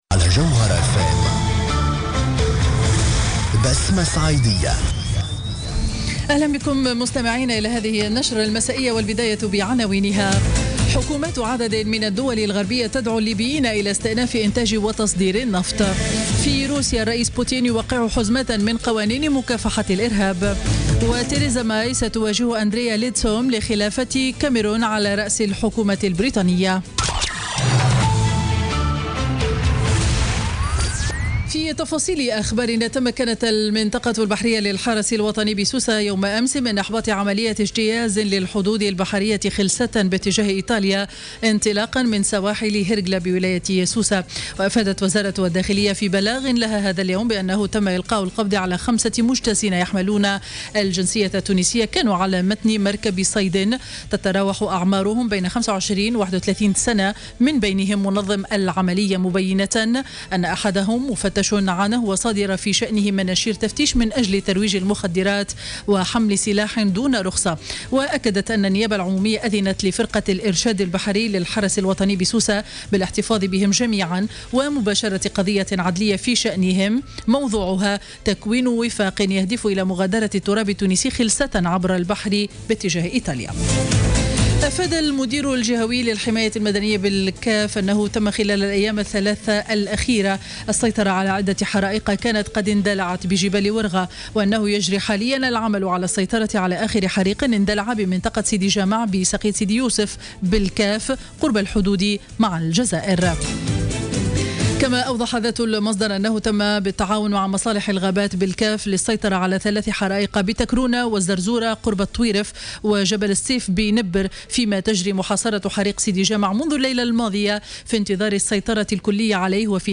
Journal Info 19h00 du jeudi 7 juillet 2016